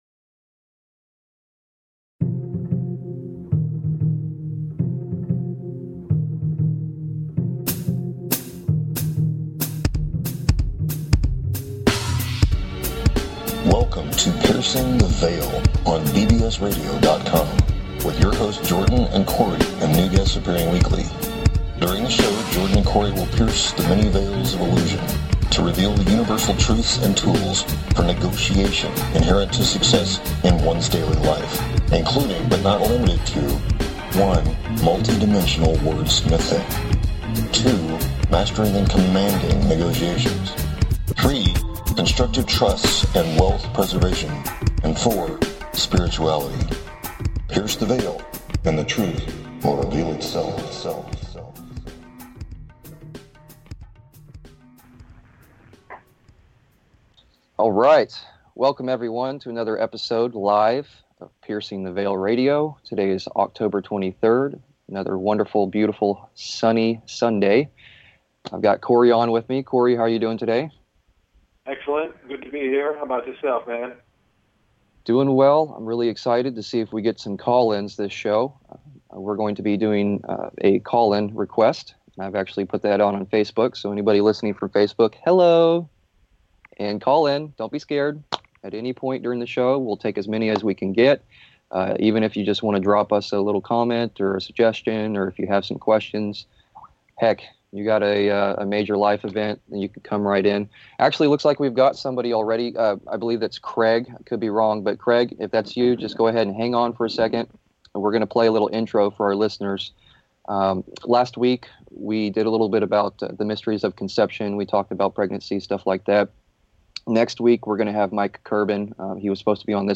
Guest Call In Show